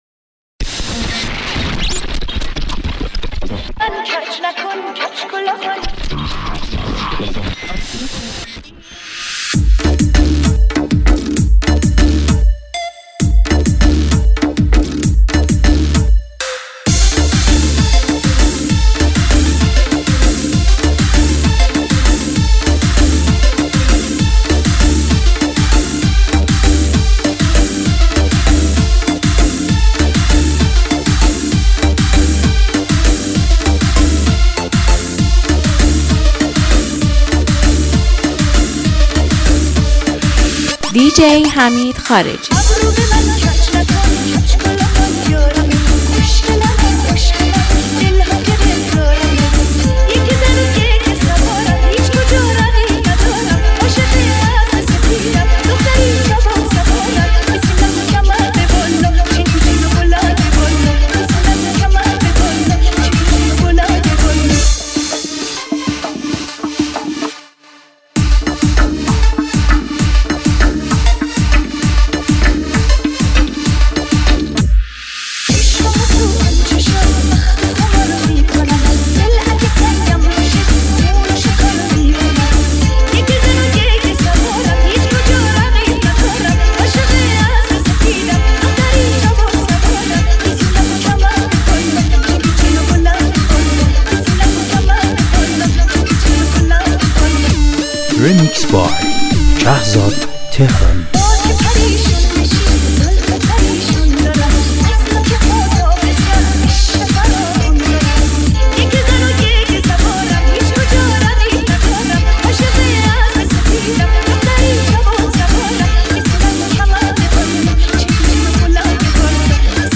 ریمیکس شاد و خاطره‌انگیز